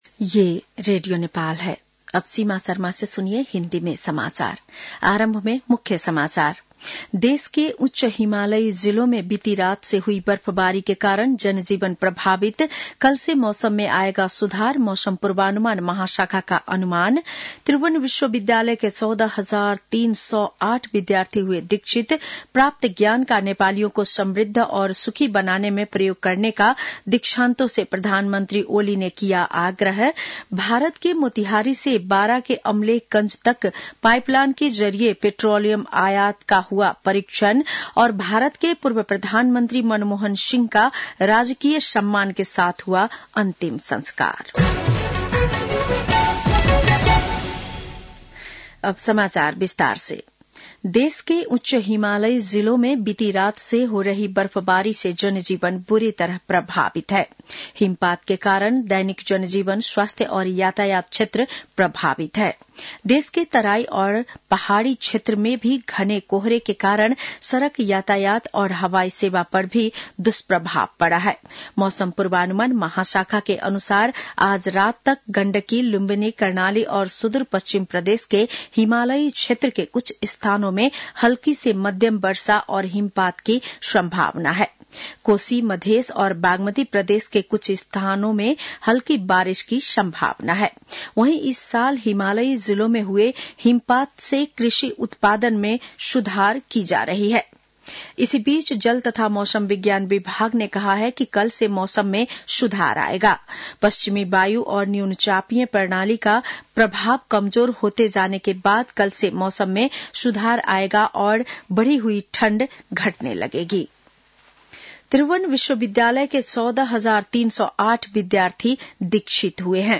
बेलुकी १० बजेको हिन्दी समाचार : १५ पुष , २०८१
10-pm-hindi-news-9-13.mp3